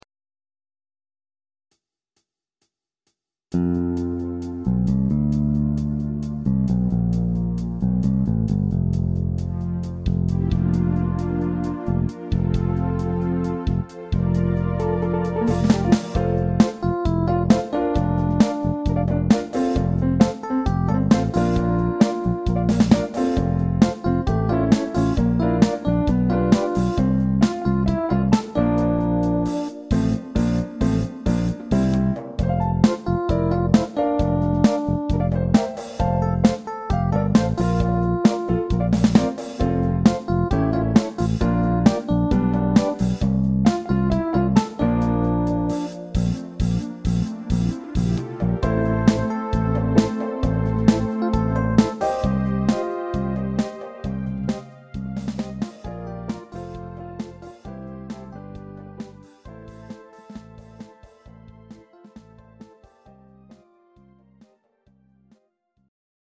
Rock Ballads